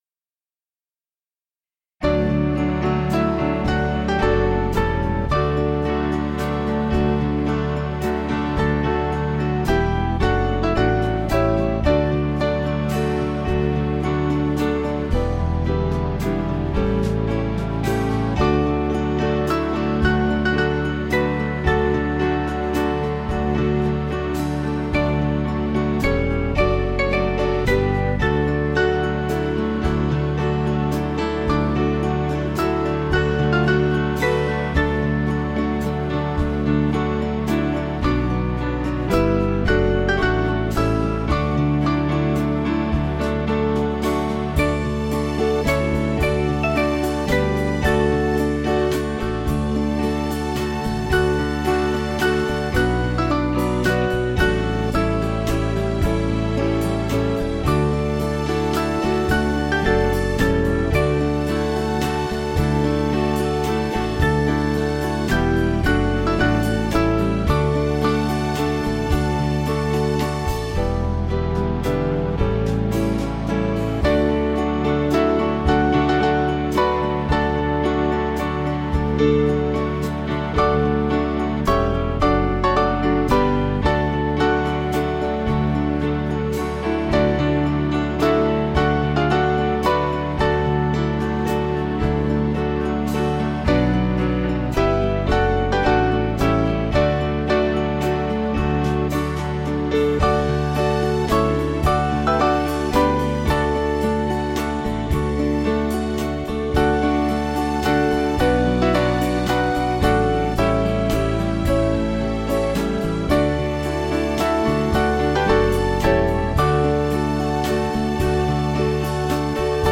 Piano/Small Band
Small Band